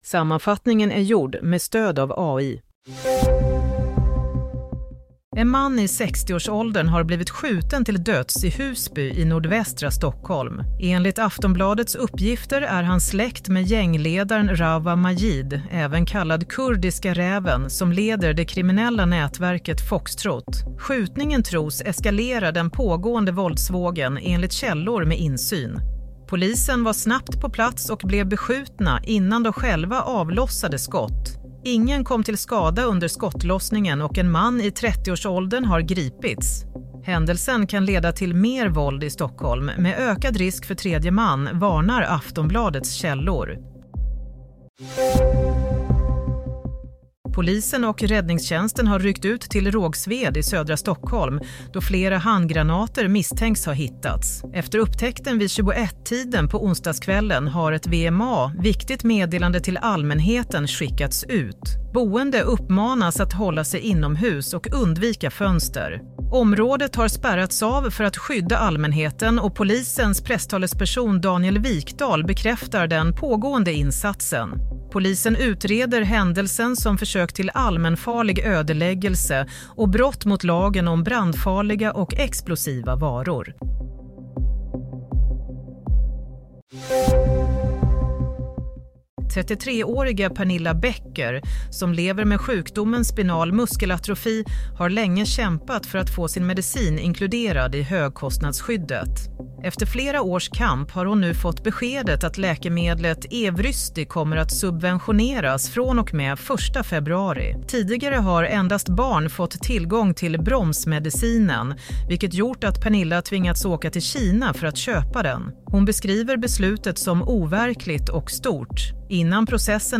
Play - Nyhetssammanfattning – 29 januari 22:00
Sammanfattningen av följande nyheter är gjord med stöd av AI.